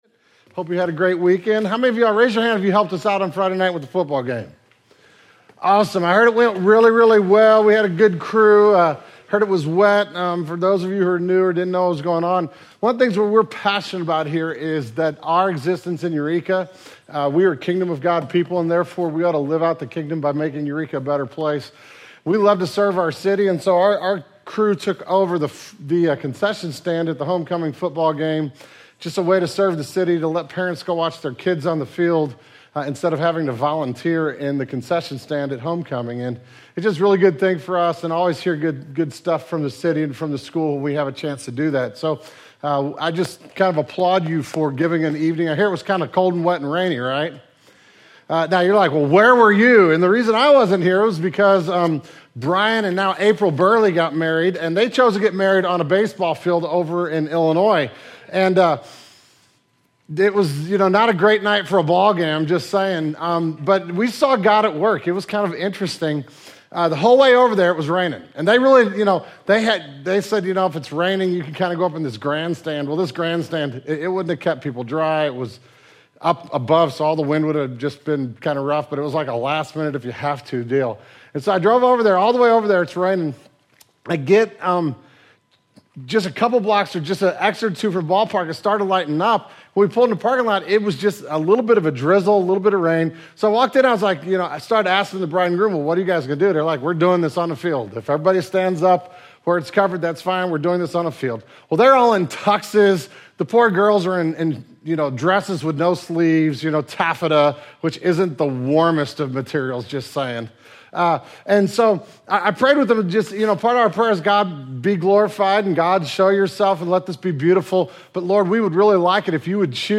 This morning we are starting a new sermon series on the Old Testament prophetic book of Malachi.